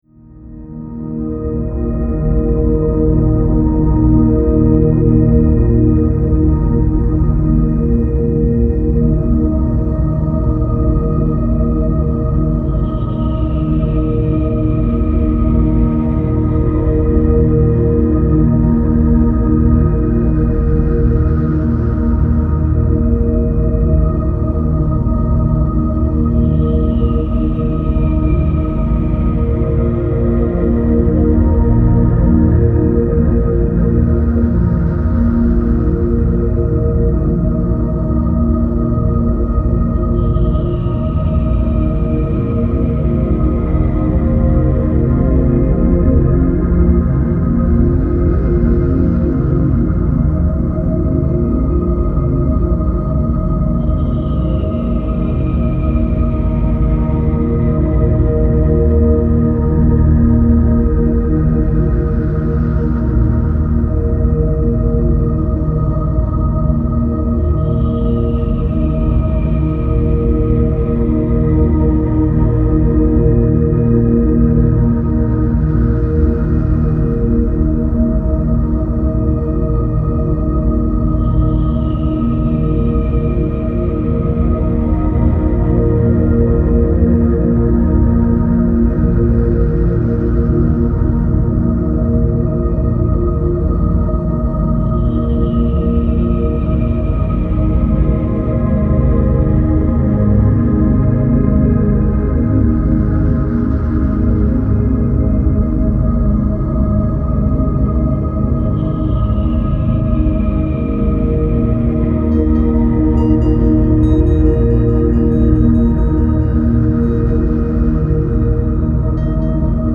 Style Style Ambient, EDM/Electronic
Mood Mood Calming, Relaxed
Featured Featured Synth
BPM BPM 70